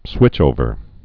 (swĭchōvər)